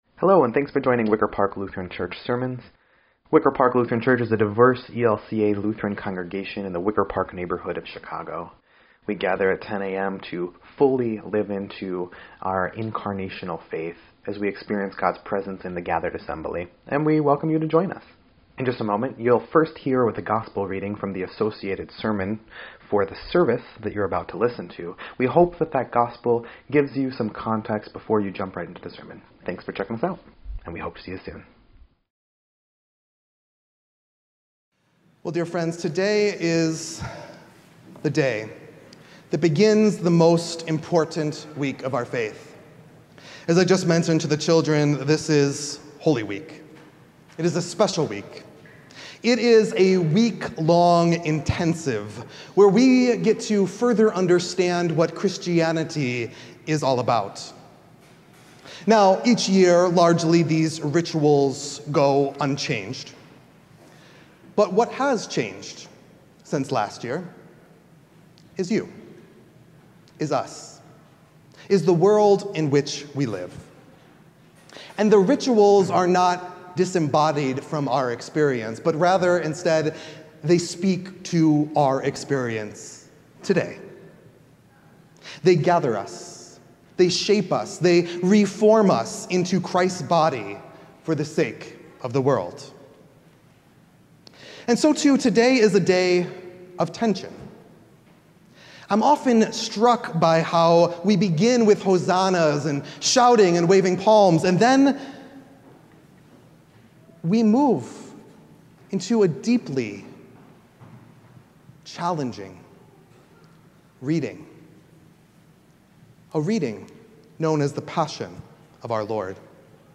3.29.26-Sermon_EDIT.mp3